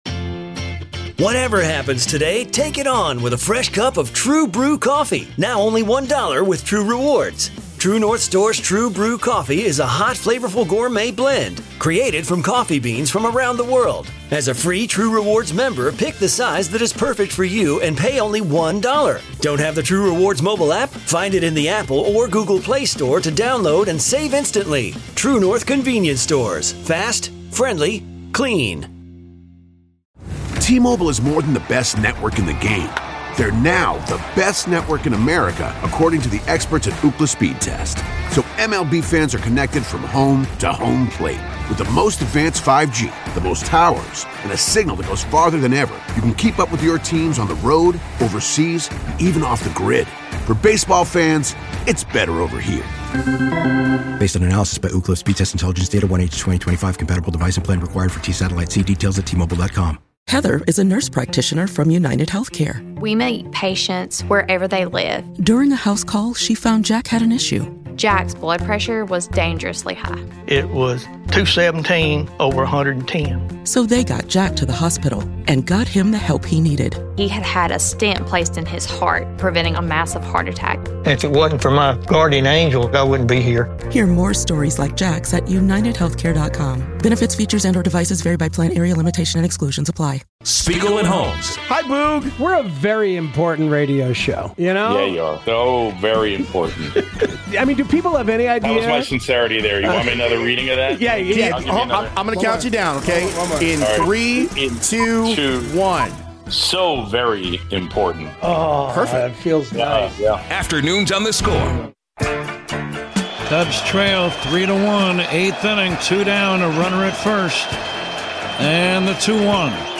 1 Interview Only w